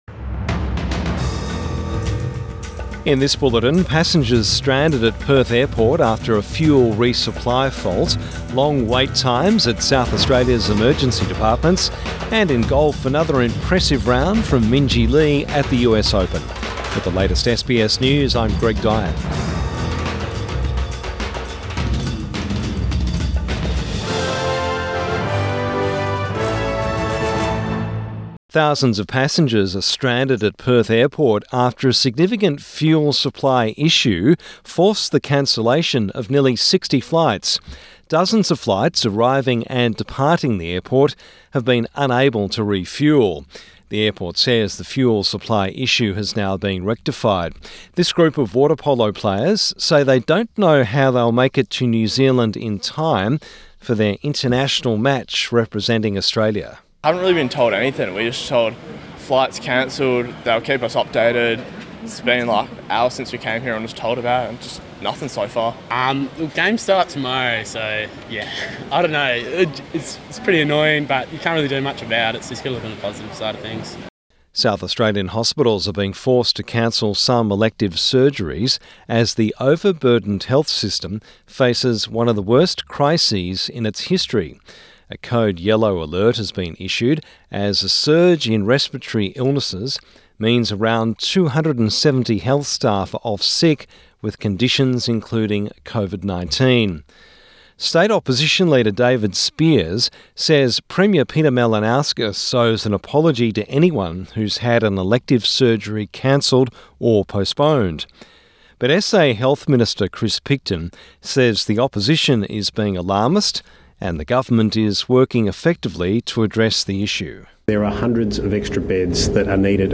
Evening News Bulletin 1 June 2024